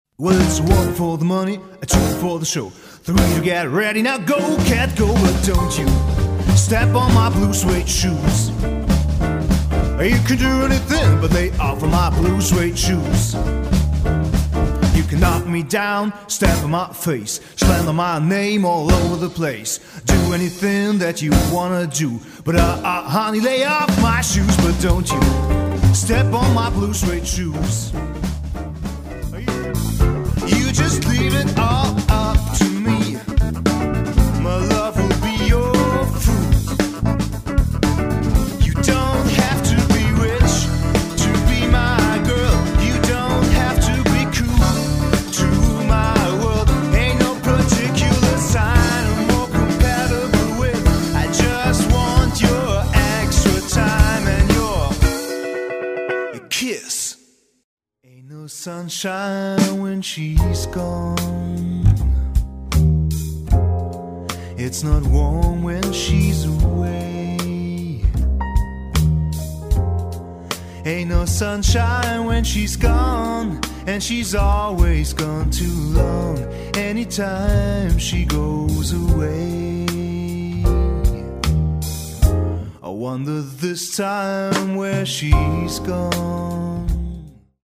Ob Jazz, Rock, Pop - die Liveband hat Ihren eigenen Sound.
Medley Party (Studio) | Repertoire Party
für Swing, Pop, Rock, Funk Cover Band Köln